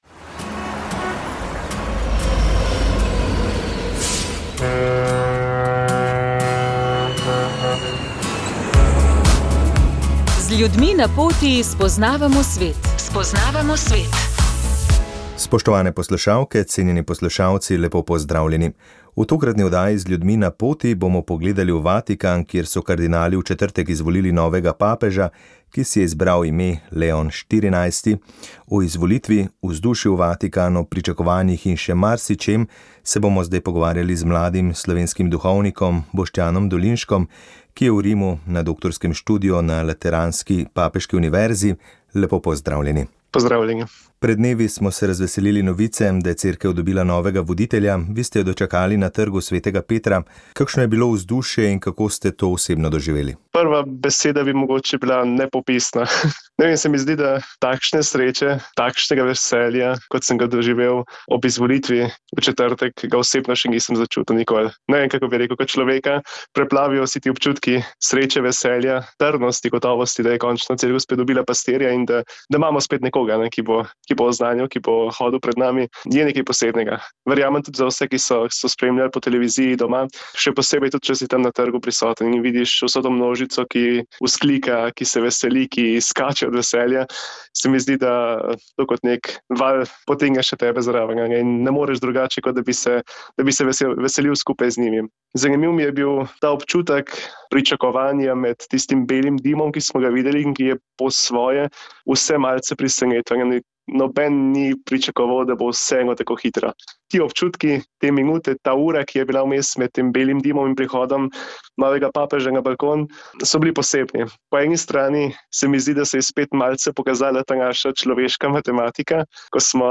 V oddaji smo gostili nogometaša nigerijskih korenin, ki ga je v Slovenijo pripeljala najbolj priljubljena igra na svetu, tukaj se je zaljubil in si ustvaril družino, nogometna pot pa ga je vodila v Turčijo.